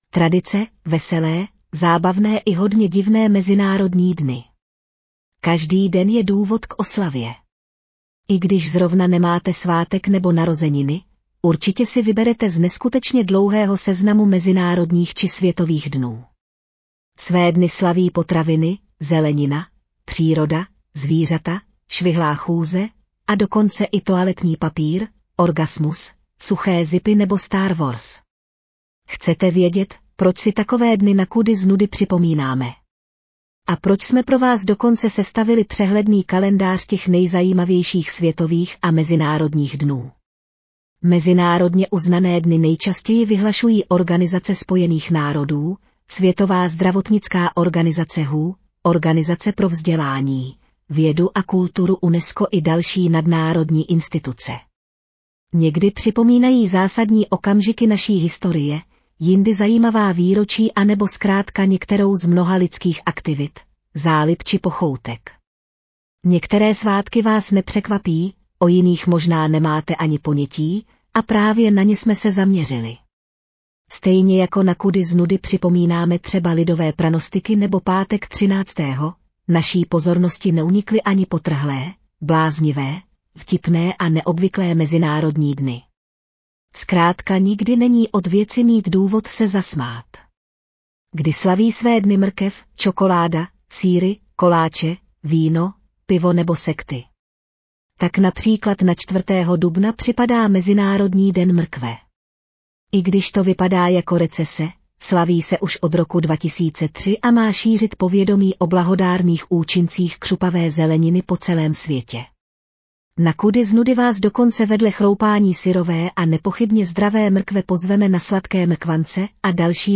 Audio verze článku Tradice: veselé, zábavné i hodně divné mezinárodní dny